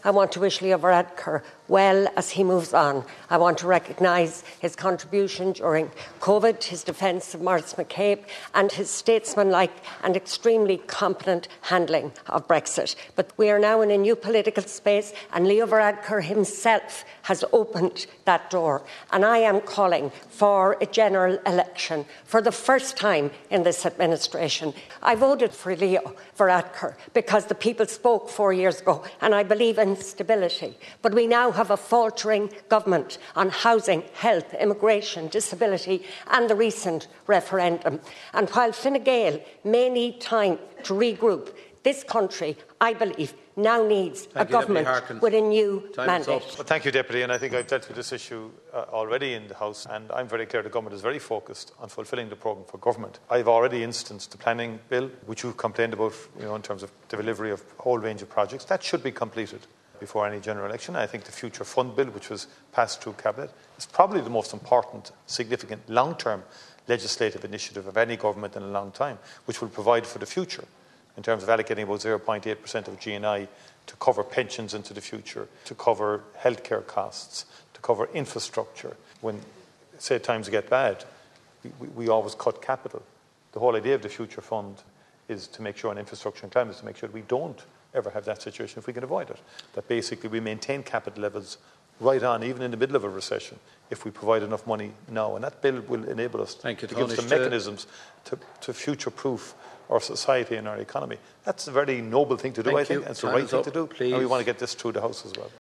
Responding in the Dail to South Donegal Deputy Marian Harkin, Mr Martin said initiatives such as the Future Fund Bill are important, and he is committed to getting them passed………